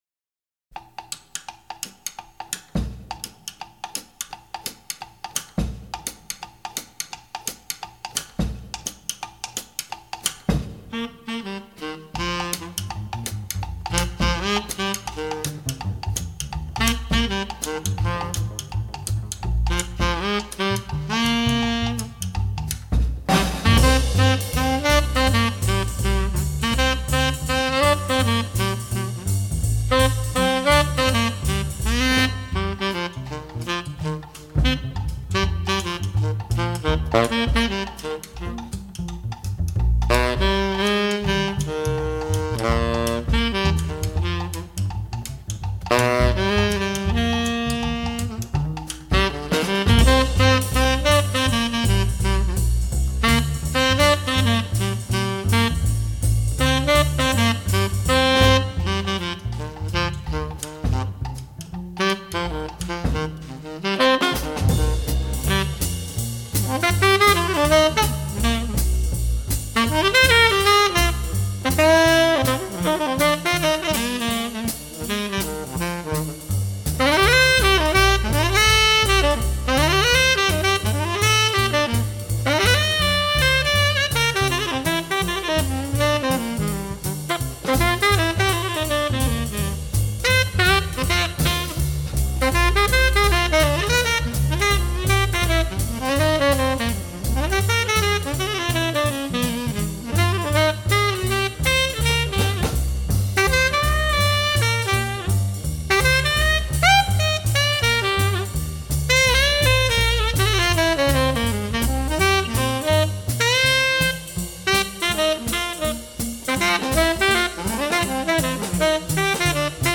★ 鼓點清脆俐落、貝斯彈跳有形、薩克斯風氣韻連綿，多年來音響迷們喜愛的專輯終於推出再進化的版本！